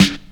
Royality free snare drum sample tuned to the G note. Loudest frequency: 1233Hz
• Classic Hip-Hop Steel Snare Drum Sound G Key 12.wav
classic-hip-hop-steel-snare-drum-sound-g-key-12-1Kz.wav